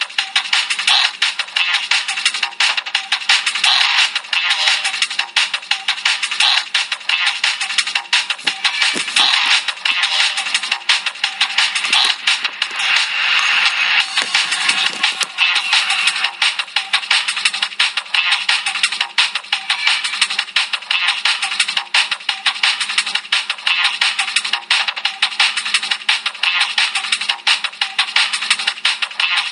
TOP >Vinyl >Drum & Bass / Jungle